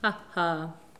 Les sons ont été découpés en morceaux exploitables. 2017-04-10 17:58:57 +02:00 174 KiB Raw Permalink History
haha_01.wav